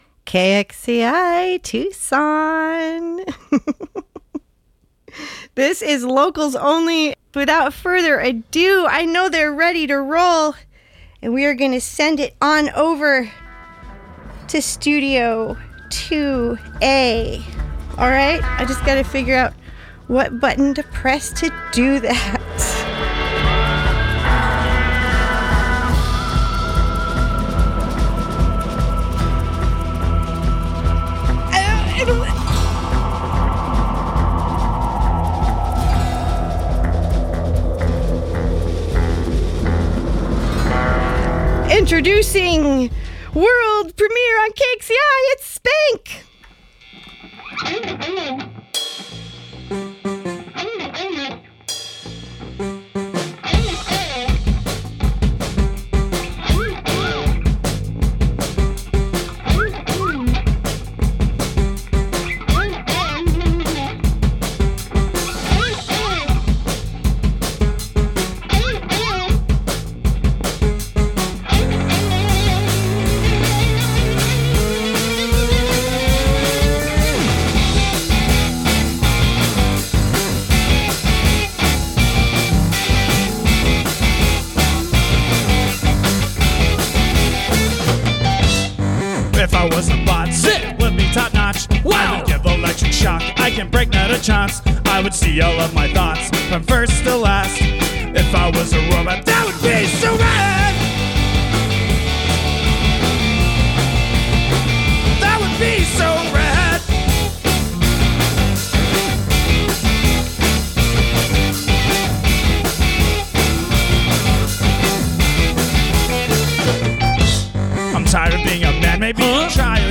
Listen to the live performance + interview here!
Inspired by funk, disco, psych, new wave
drums
bass
guitar
synth punk